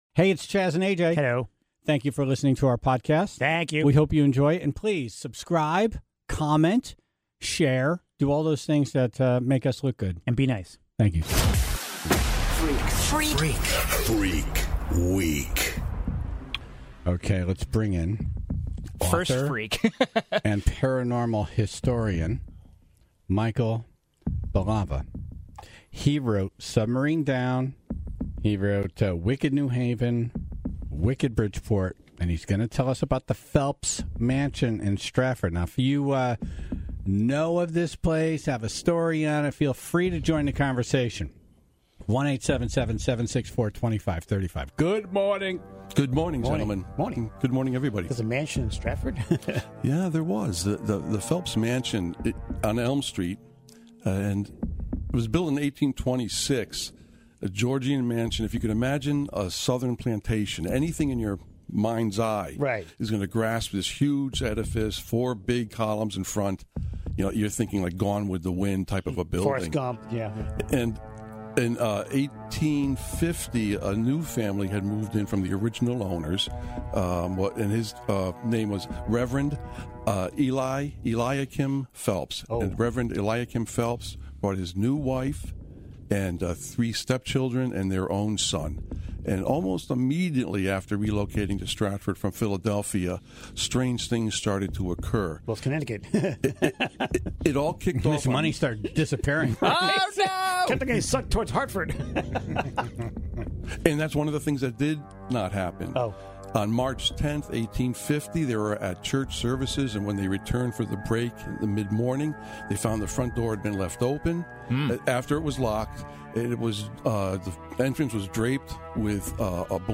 The very first caller educated everyone on the very real phobia of all things ketchup.